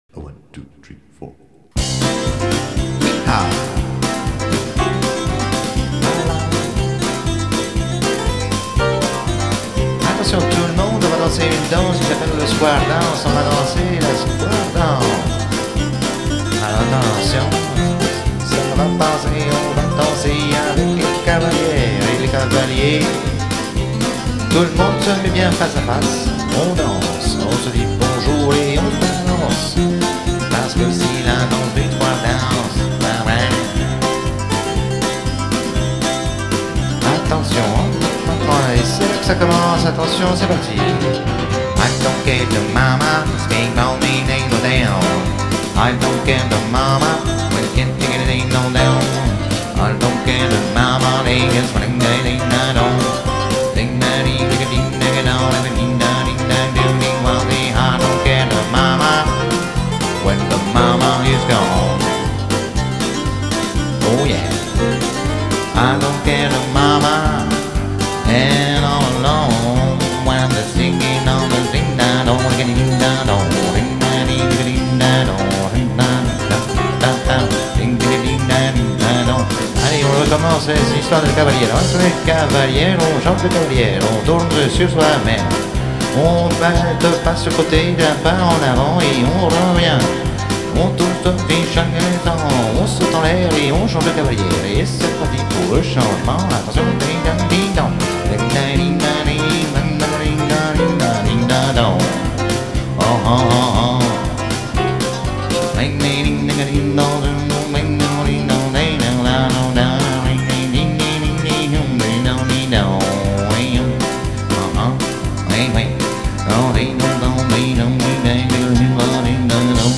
Salsa pas craignos (avec solos de flutos). 2005